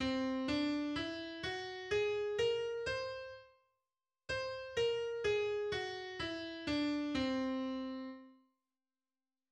Tervetoonhelirida, naaberhelide intervallidega 1 (üks) tervetoon, on olnud sporaadiliselt kasutusel heliteoste sees.
Tonleiter_c-ganzton.mid.mp3